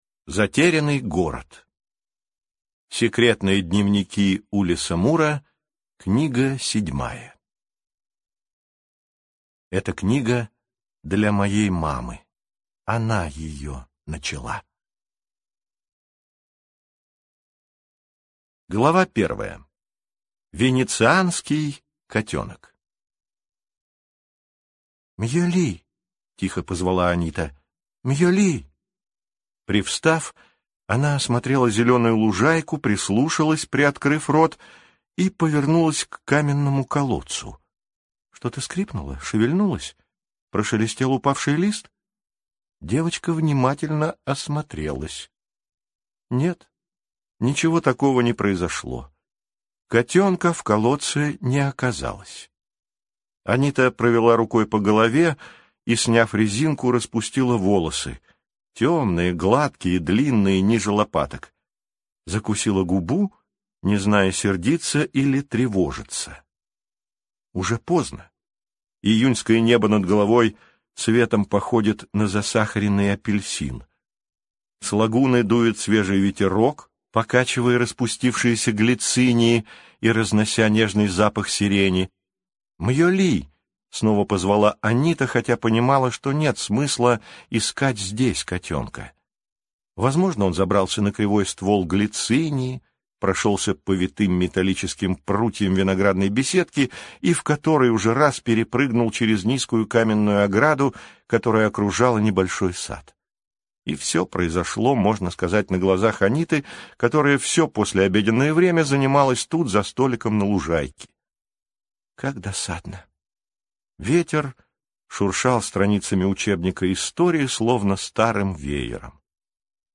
Аудиокнига Затерянный город | Библиотека аудиокниг